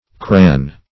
Search Result for " cran" : Wordnet 3.0 NOUN (1) 1. a capacity unit used for measuring fresh herring ; The Collaborative International Dictionary of English v.0.48: Cran \Cran\ (kr[a^]n), Crane \Crane\ (kr[=a]n), n. [Scot., fr. Gael. crann.]